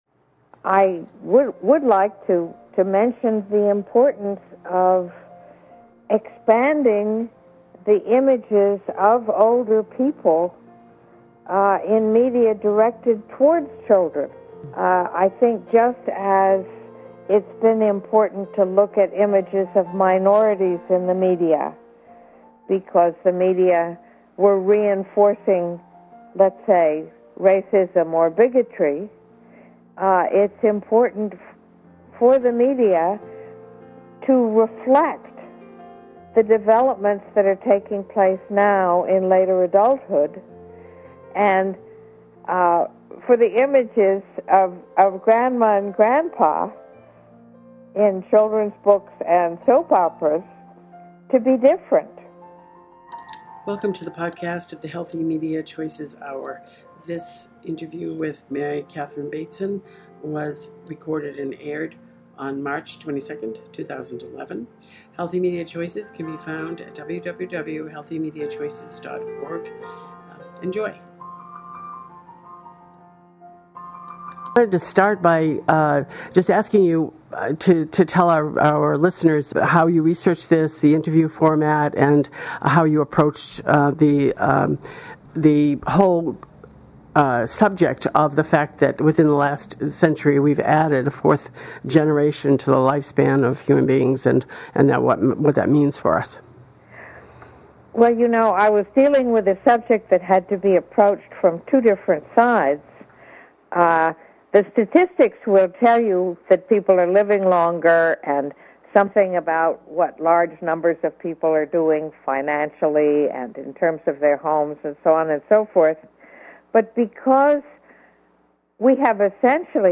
Listen to Mary Catherine Bateson speak about her latest book: